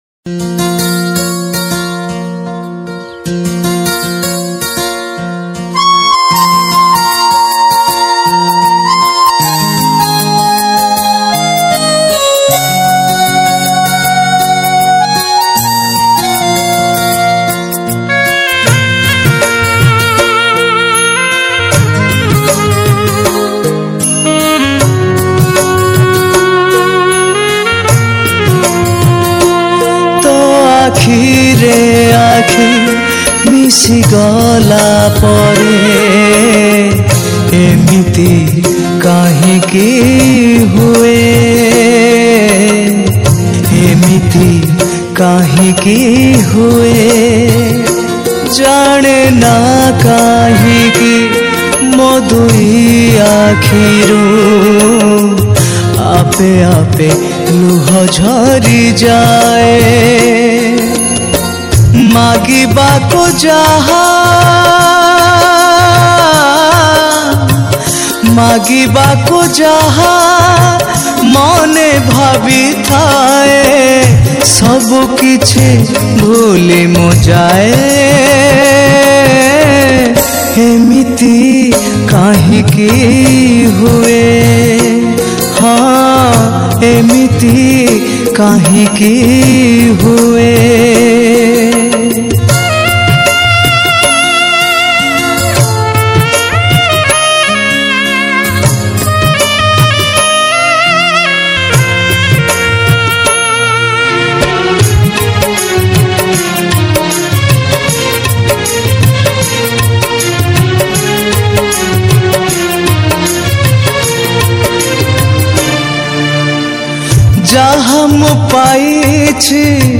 Superhit Odia Bhajan